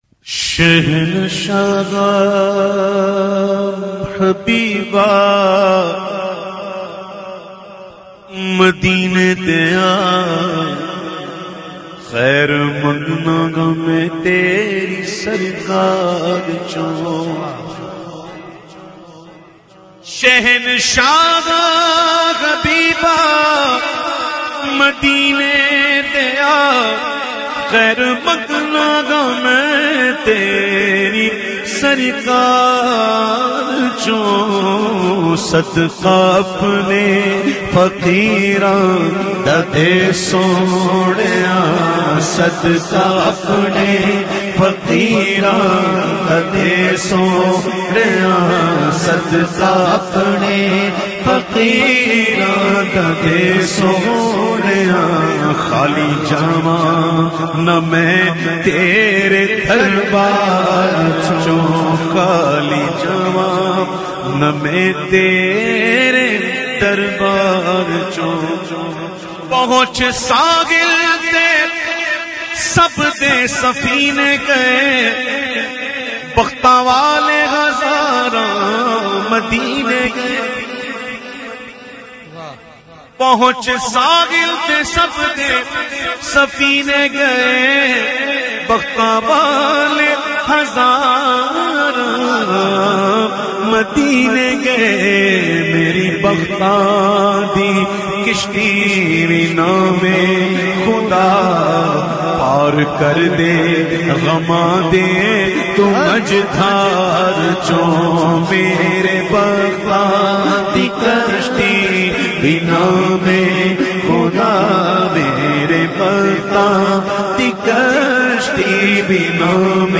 Naat Sharif
recited by famous Naat Khawan of Pakistan